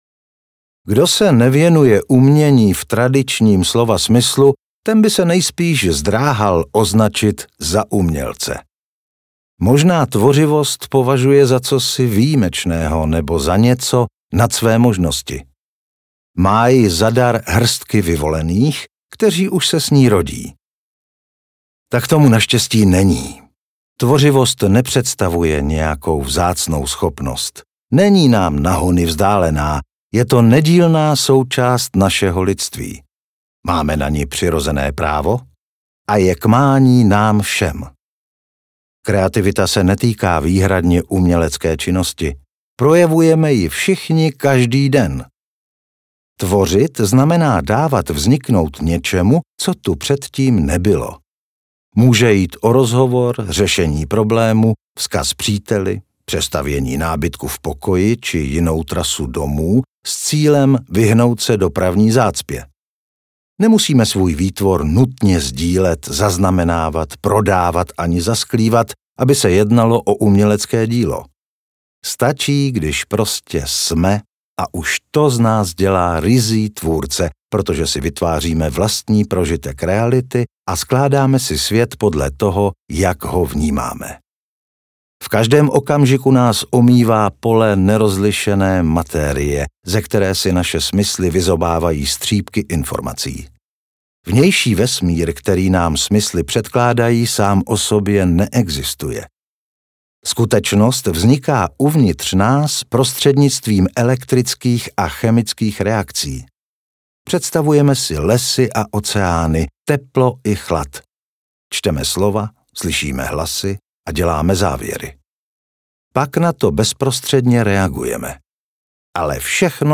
Tvůrčí akt audiokniha
Ukázka z knihy